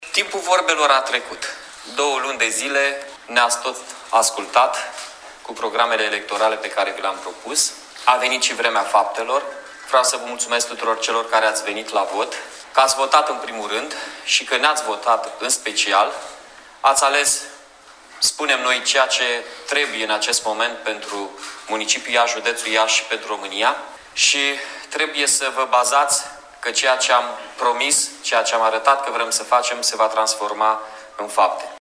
Primarul Mihai Chirica a declarat, aseară, că a trecut timpul vorbelor şi că votul dat de cetăţeni arată că ‘Iaşul a ales un alt drum’, cel al schimbării, care ar trebui să reducă decalajele dintre estul şi vestul ţării.